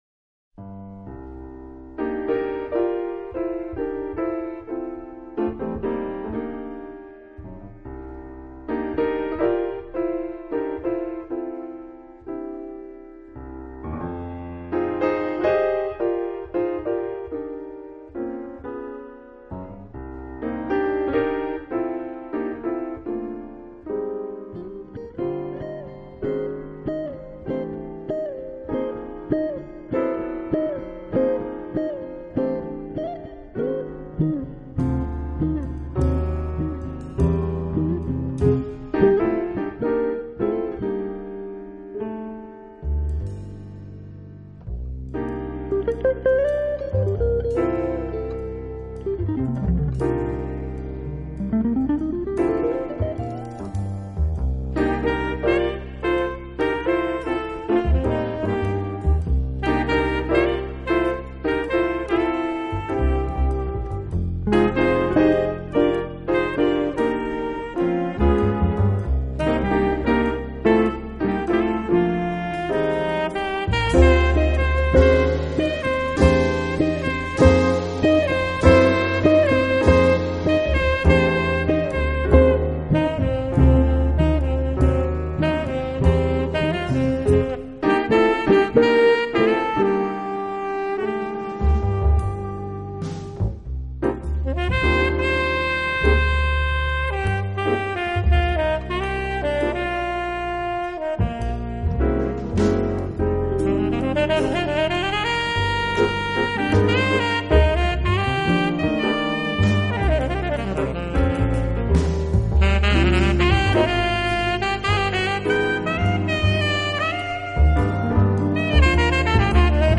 【爵士吉他】
音乐类型：Jazz